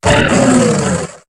Cri de Diamat dans Pokémon HOME.